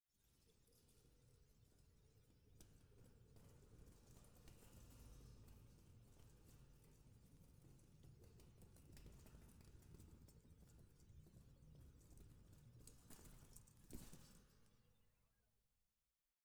Metal_74.wav